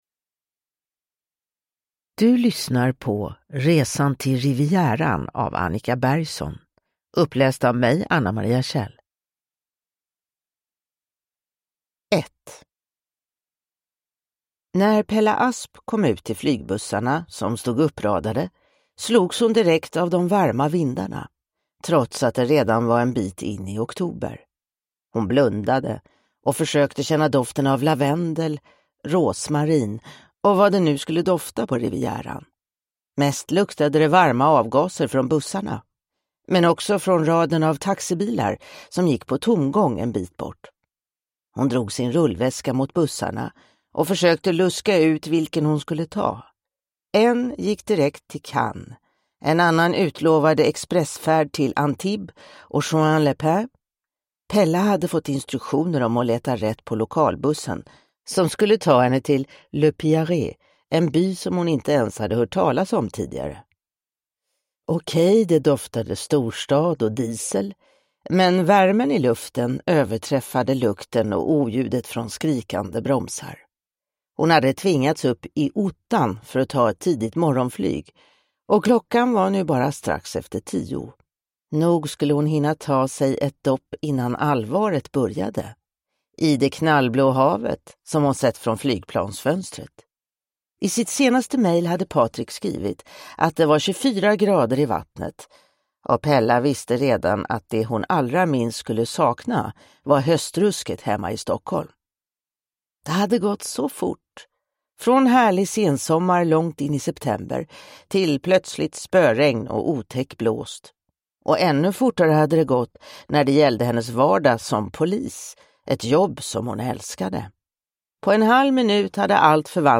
Resan till Rivieran – Ljudbok